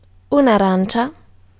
Ääntäminen
Synonyymit arancio Ääntäminen Tuntematon aksentti: IPA: /a.ˈran.tʃa/ IPA: /a.ˈran.tʃe/ Haettu sana löytyi näillä lähdekielillä: italia Käännös Ääninäyte Substantiivit 1. orange UK CA Suku: f .